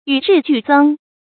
yǔ rì jù zēng
与日俱增发音
成语正音增，不能读作“zhēnɡ”。